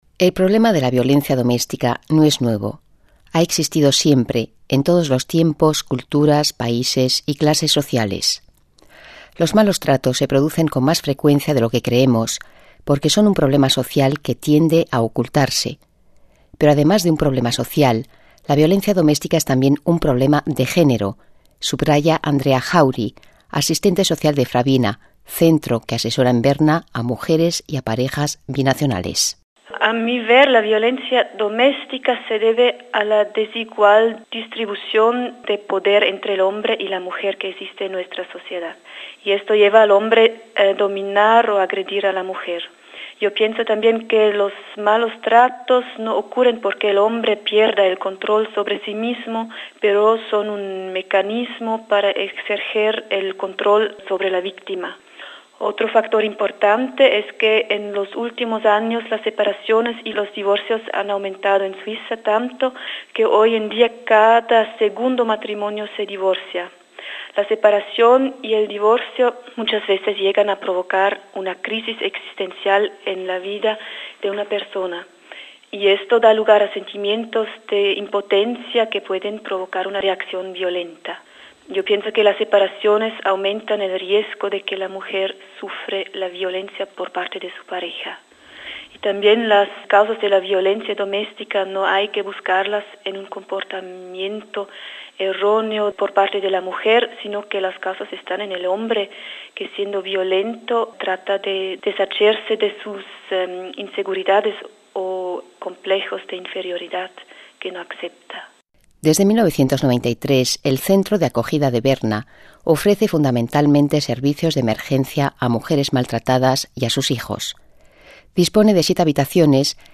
El año pasado, unas 64 madres y 57 niños buscaron refugio en la casa de acogida de mujeres maltratadas de Berna. Un reportaje